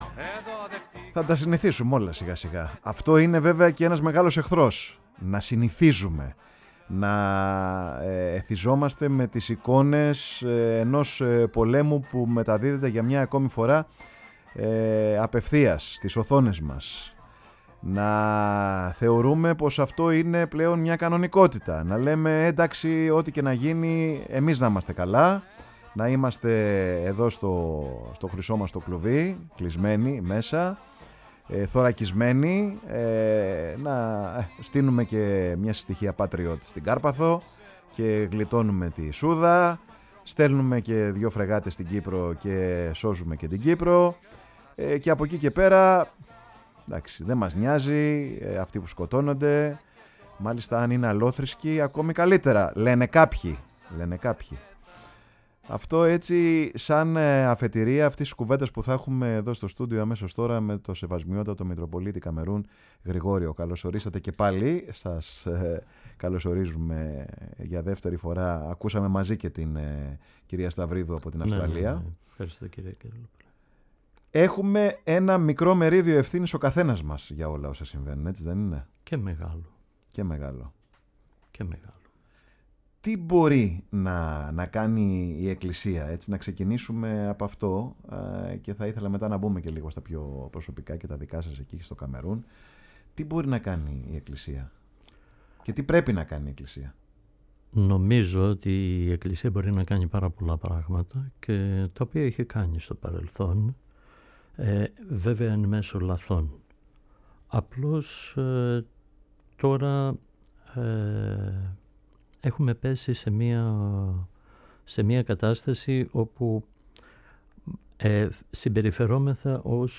Καλεσμένος στην εκπομπή “Πάρε τον Χρόνο σου”, στο στούντιο της Φωνής της Ελλάδας ήταν ο Σεβασμιότατος Μητροπολίτης Καμερούν Γρηγόριος. Αναφέρθηκε στην κατάσταση που επικρατεί σήμερα στον πλανήτη, με τους πολέμους και τις εμφύλιες διαμάχες, την ανάγκη να επιστρέψουμε στα βασικά στοιχεία της διδασκαλίας του Χριστού, όπως μας έχει μεταφερθεί από τους Μακαρισμούς στο Κατά Ματθαίον Ευαγγέλιο και να επαναλαμβάνουμε το μανιφέστο της νέας Δικαιοσύνης.
Ακούστε την πολύ ενδιαφέρουσα συνέντευξη του Μητροπολίτη Καμερούν στη Φωνή της Ελλάδας.